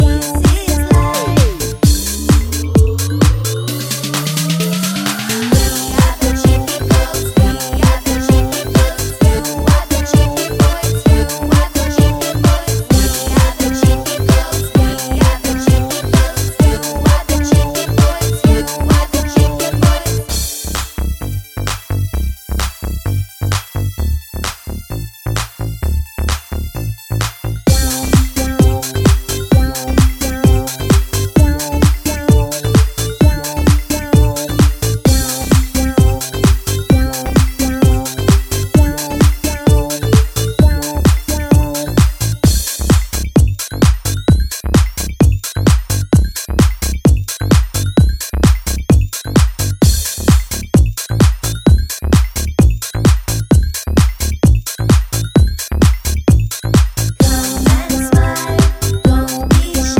No Backing Vocals Comedy/Novelty 3:21 Buy £1.50